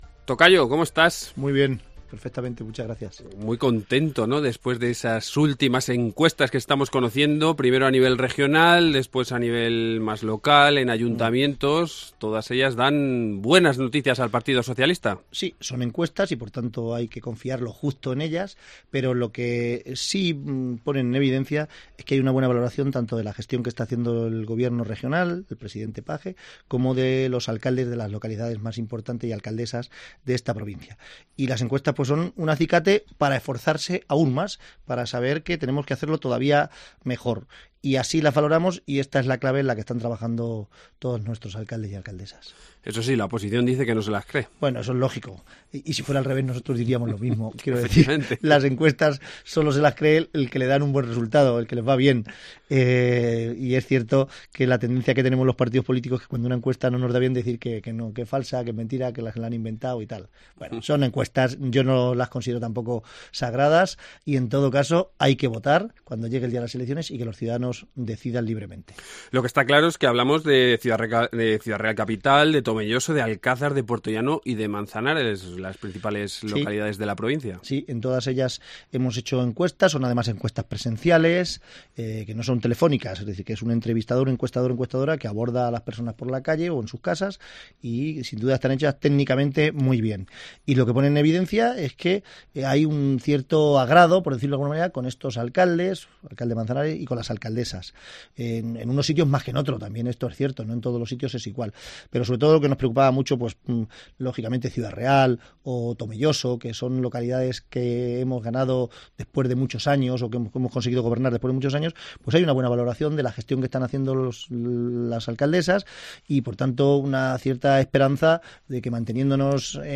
Ya me acompaña en directo en los estudios de Cope el presidente de la Diputación y secretario general de los socialistas ciudadrealeños José Manuel Caballero y con el vamos a hablar de toda la actualidad política provincial y de las últimas noticias de la Diputación.